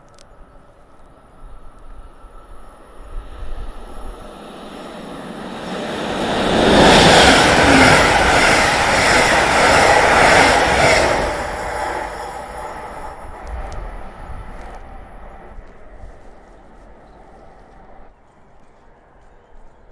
交通 交通 " 有轨电车在旧金山市场街通过
描述：在旧金山市场街上经过的旧电车之一。 在明亮的春日使用Zoom H4n和Rode NTG2进行录制。记录陪伴朋友街头摄影展。
Tag: 运输 交通 铁路 火车 轨道 三藩市 铁路 电车 电车 现场记录 有轨电车 城市